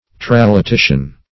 Search Result for " tralatition" : The Collaborative International Dictionary of English v.0.48: Tralatition \Tral`a*ti"tion\, n. [See Tralatitious .] A change, as in the use of words; a metaphor.